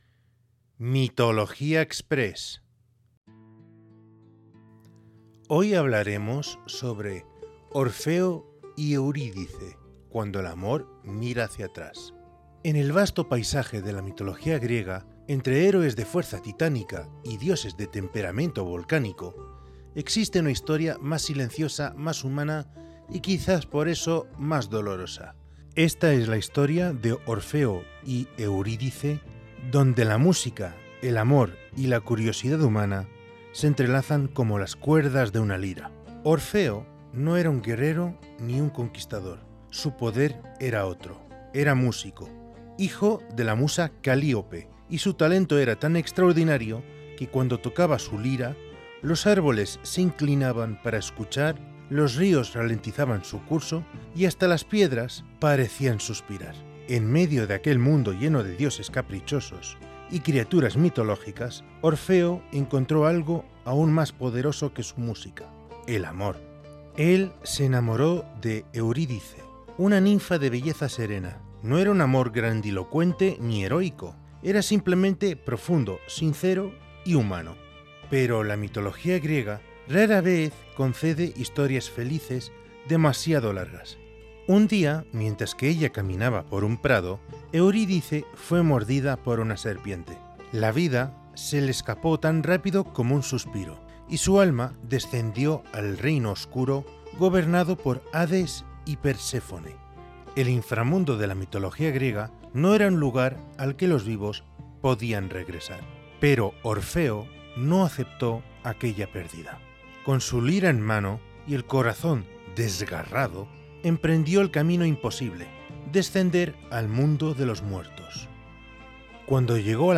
A través de una narración envolvente, descubriremos cómo Orfeo descendió al reino de los muertos para recuperar a Eurídice, y cómo una única condición —no mirar atrás— terminó convirtiéndose en la prueba más difícil de todas.